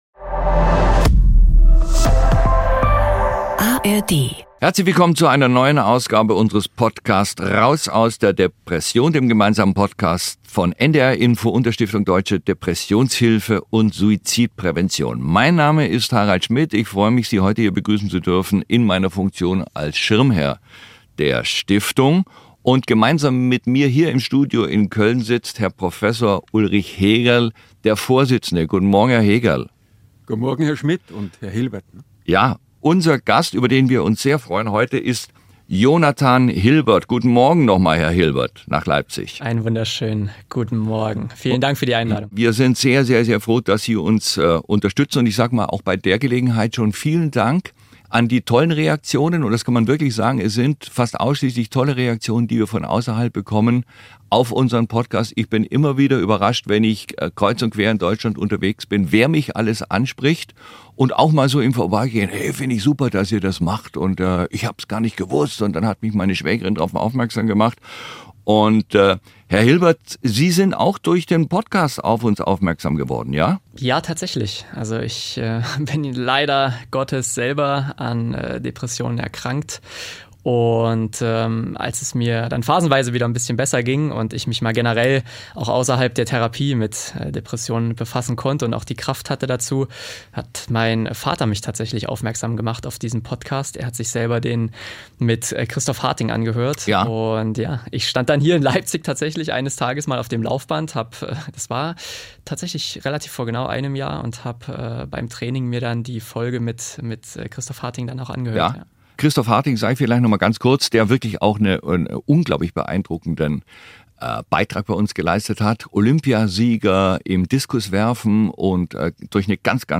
In den Monaten nach seinem Olympia-Erfolg entwickelt er eine Depression. Im Podcast Raus aus der Depression spricht Jonathan Hilbert mit Harald Schmidt darüber, wie sich die Erkrankung bei ihm bemerkbar gemacht hat – und wann er erkannt hat, dass er trotz sportlichem Leistungsdenken einen Schritt zurücktreten muss.
Es ist ein ehrlicher Austausch über Warnsignale, Selbstüberforderung und den Weg zurück zu mentaler Stabilität.